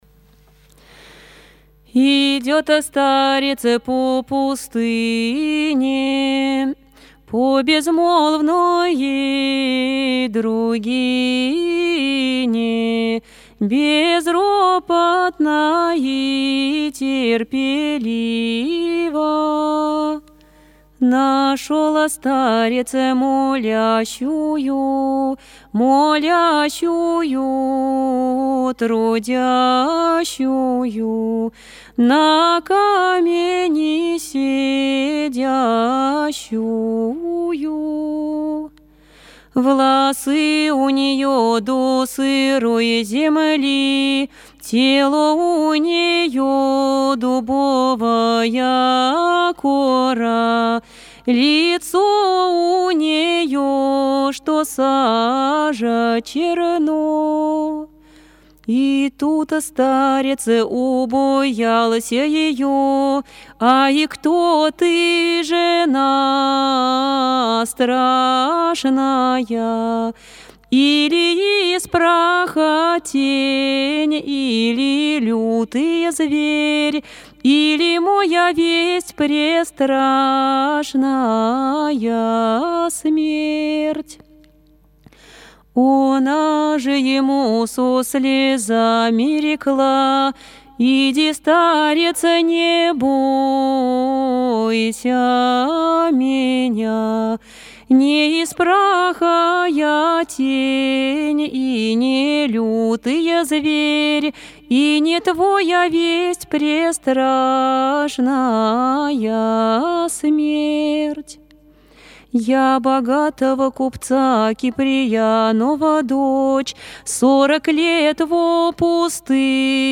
Стих